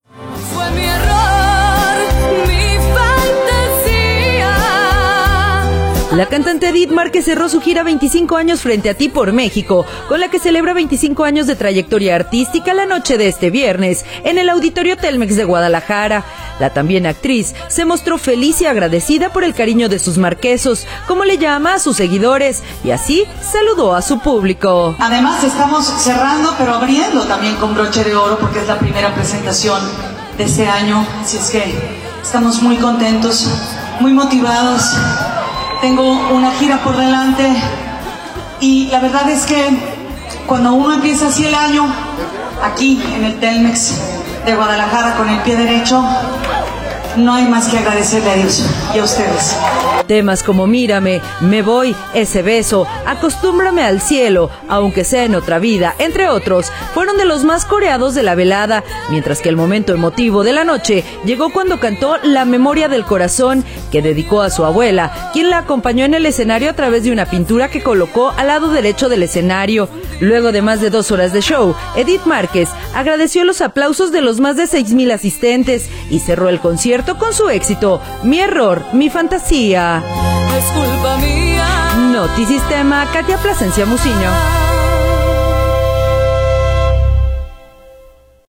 audio La cantante Edith Márquez cerró su gira “25 años frente a ti” por México, con la que celebra 25 años de trayectoria artística, la noche de este viernes en el Auditorio Telmex de Guadalajara.